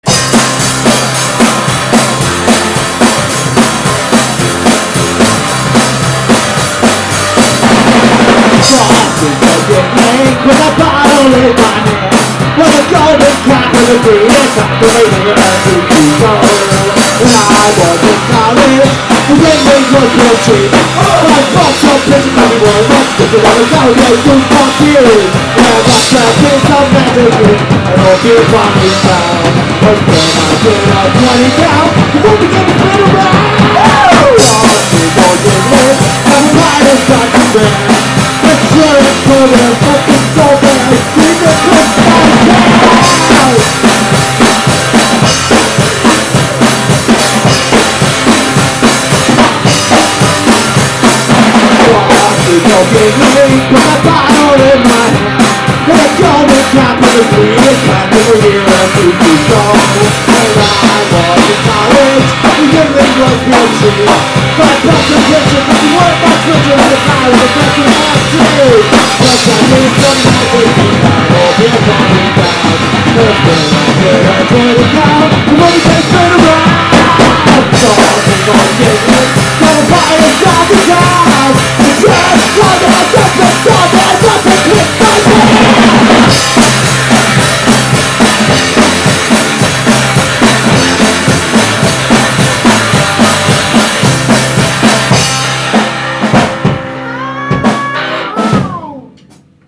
Christmas Songs!